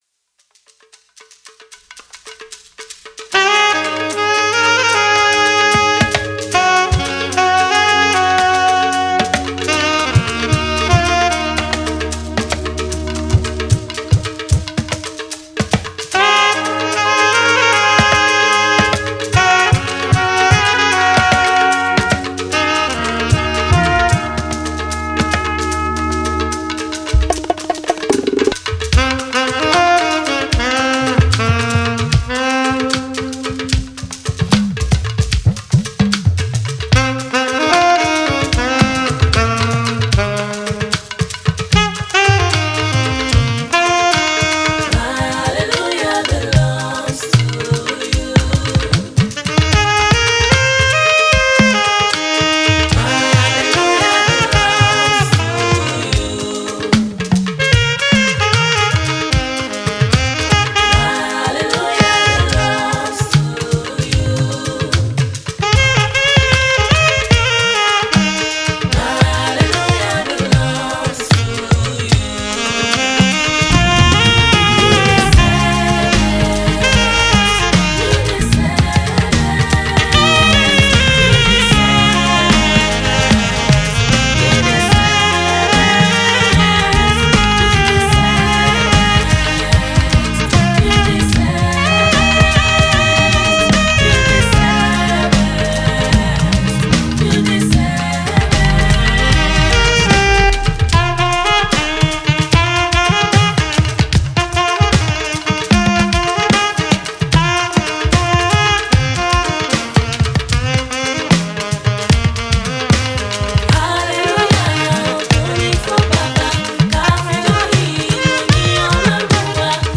soulful Saxophonist